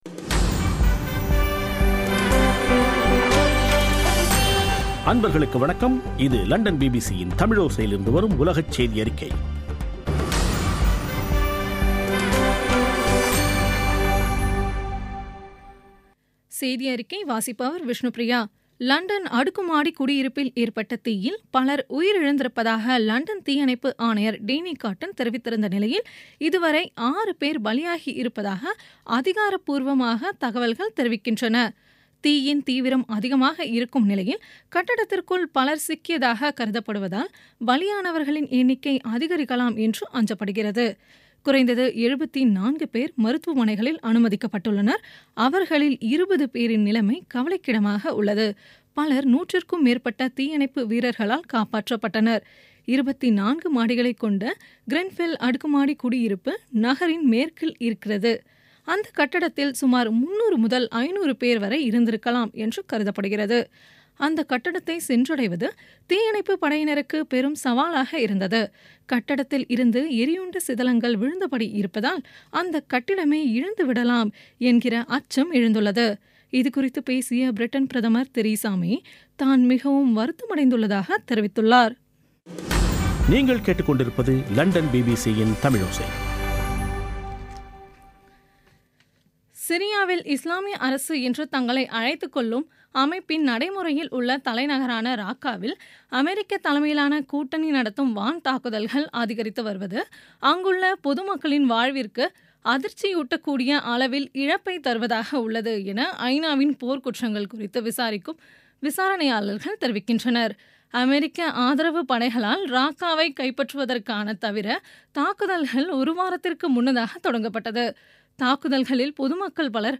பிபிசி தமிழோசை செய்தியறிக்கை (14/06/2017)